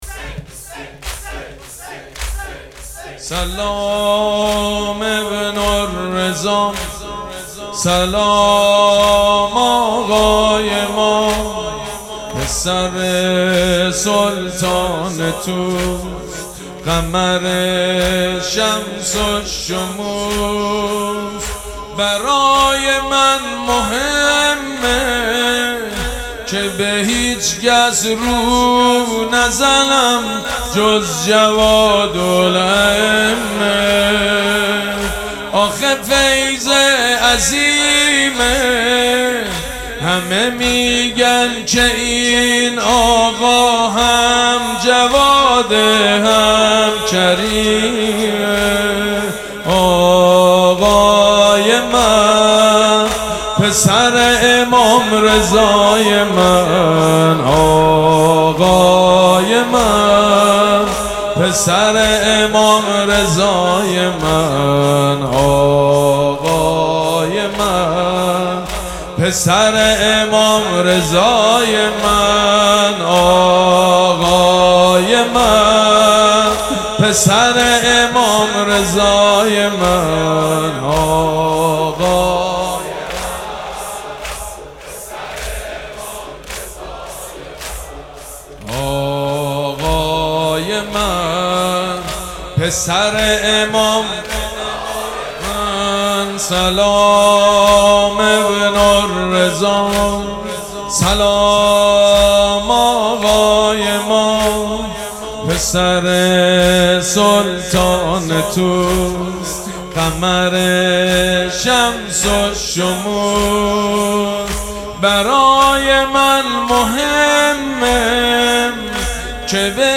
مداحی سلام ابن الرضا سلام آقای ما توسط سید مجید بنی فاطمه در مجلس ریحانه الحسین (ع) | 17 خرداد | 1403 اجراشده. مداحی به سبک زمینه اجرا شده است.